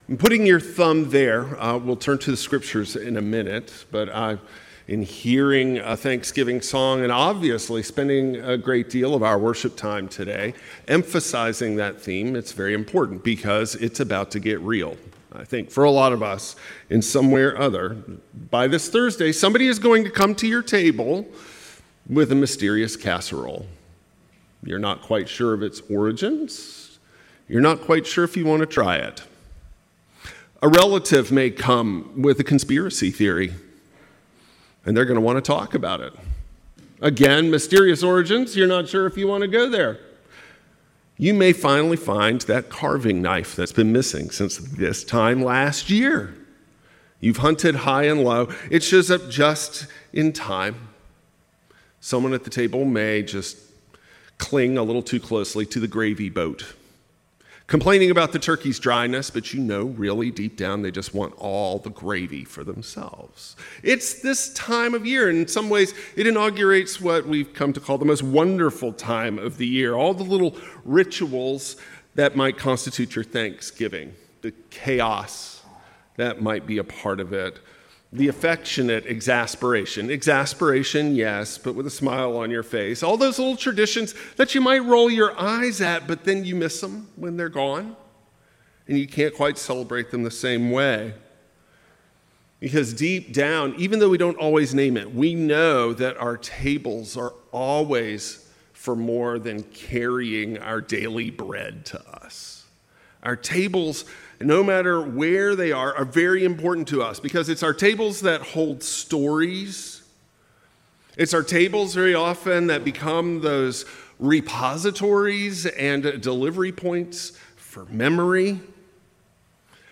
A sermon on the cosmic Christ who remembers us, hears our smallest prayers, and holds us in covenant love, from the cross to our Thanksgiving tables.
Service Type: Traditional Service